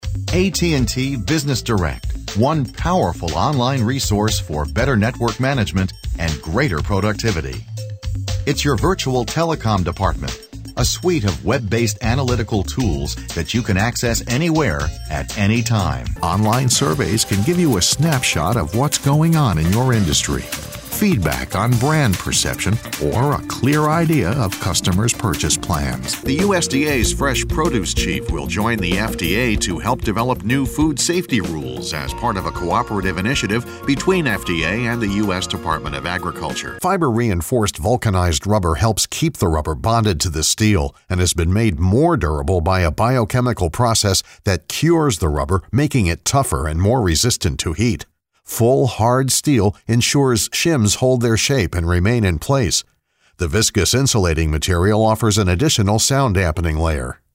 Highly experienced, credible and versatile Voice Actor/Narrator.
Sprechprobe: Industrie (Muttersprache):